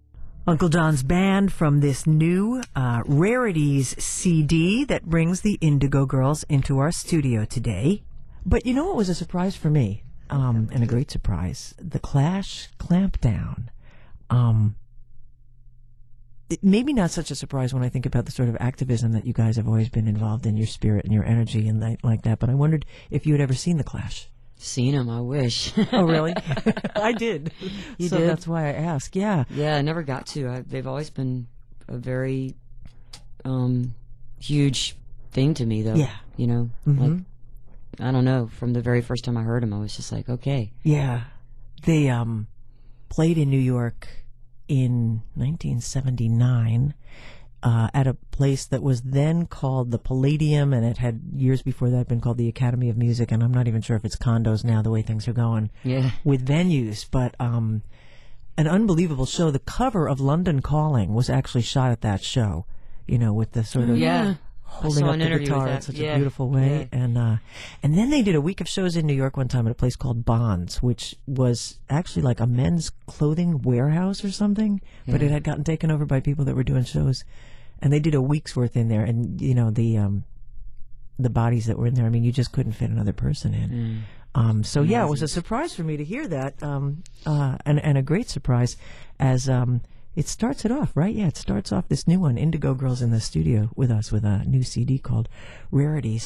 (acoustic duo show)
03. interview (1:36)